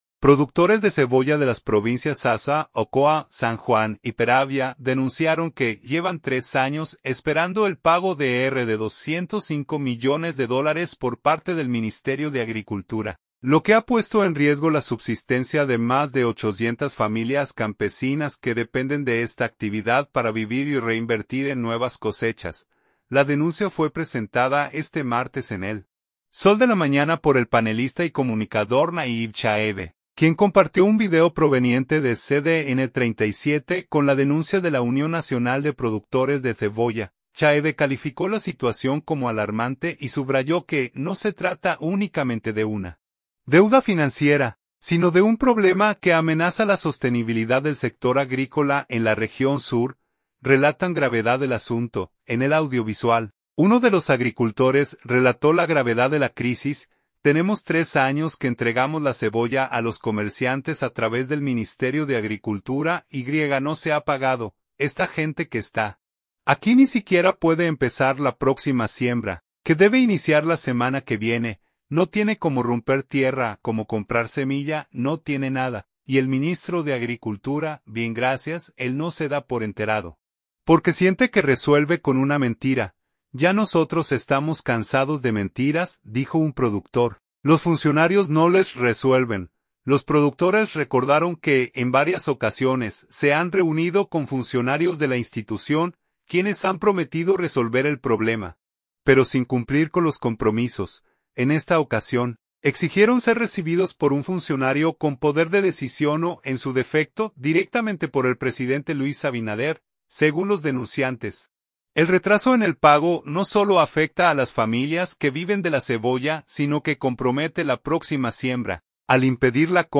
En el audiovisual, uno de los agricultores relató la gravedad de la crisis: